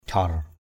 /d͡ʑʱɔr/ (t.) chảy trào = couler. jhaor darah _J<R drH máu chảy trào, trào máu = le sang coule. jhaor-jhaor _J<R-_J<R ào ào = en clapotis. aia nduec jhaor-jhaor a`%...